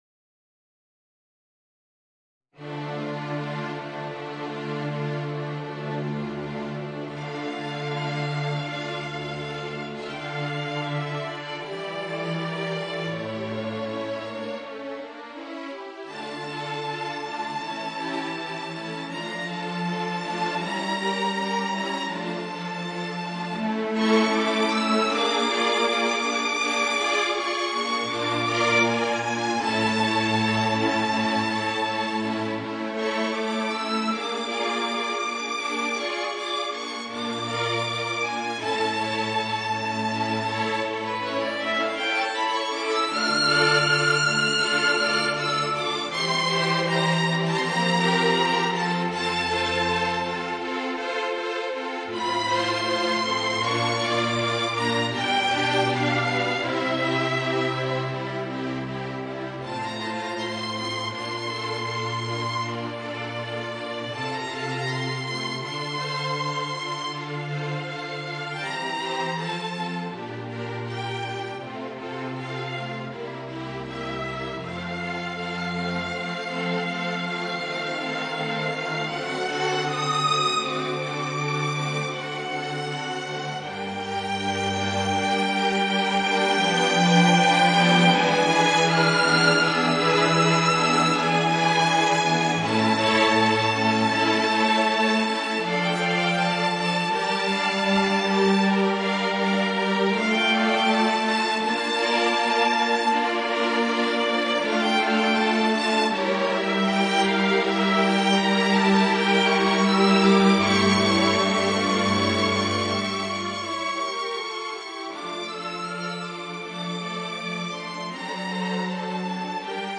Voicing: Alto Saxophone and String Quartet